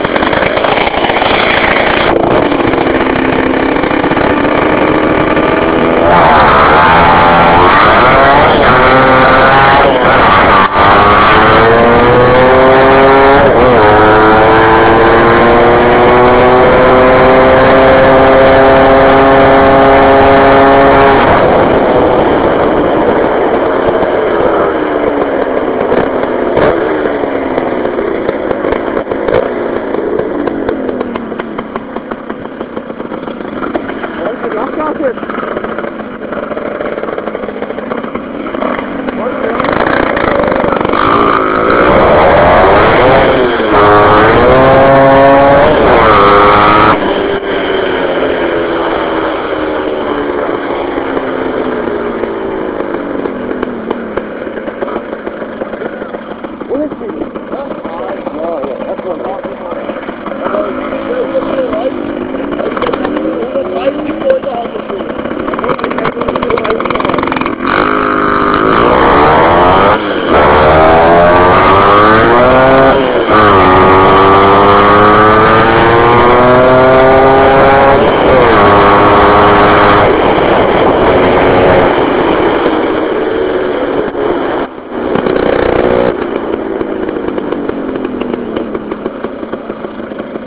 Soundfile vom lauf